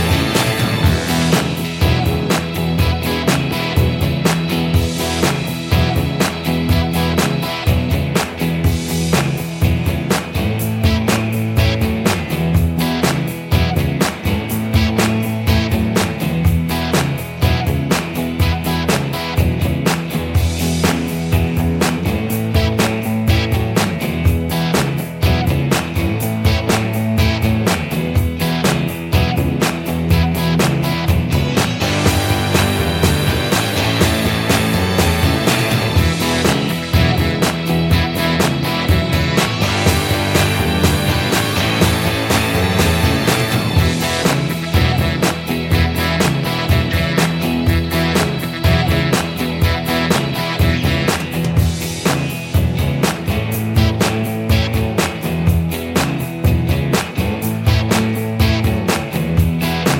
No Backing Vocals Glam Rock 4:19 Buy £1.50